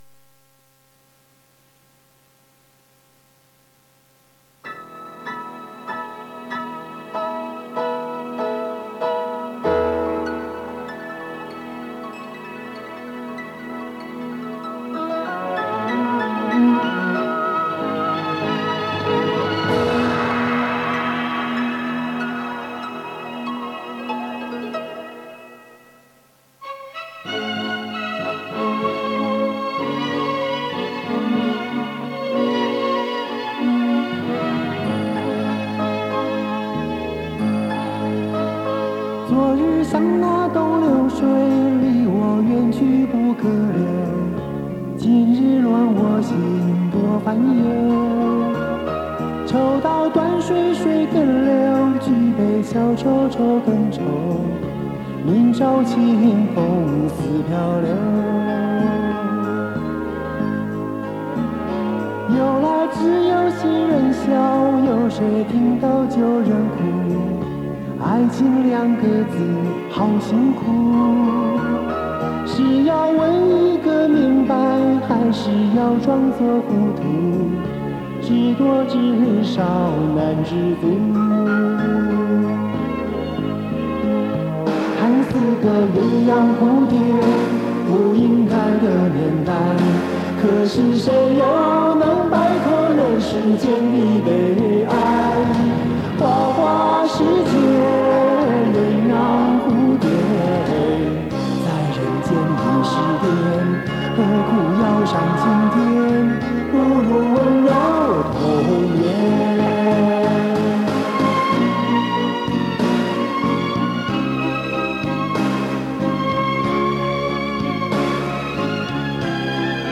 磁带数字化：2022-06-18